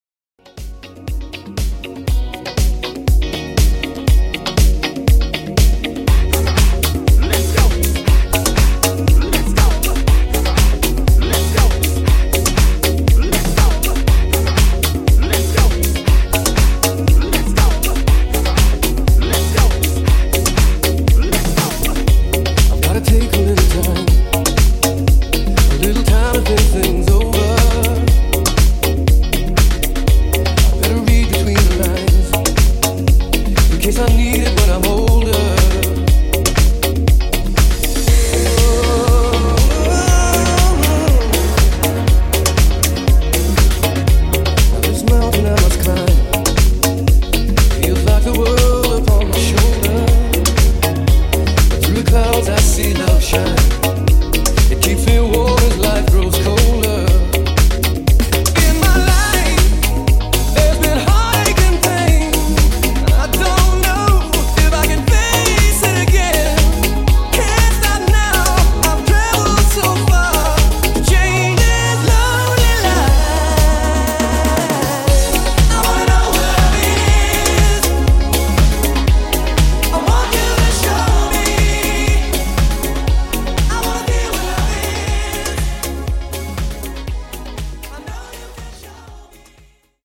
80s Club Classic Edit)Date Added